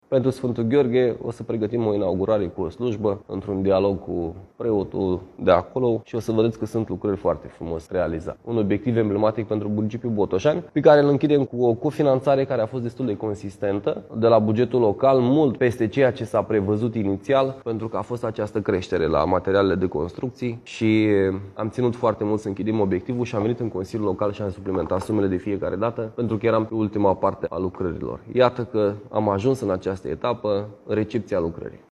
Recepția lucrărilor a demarat pe 11 august, spune primarul Cosmin Andrei: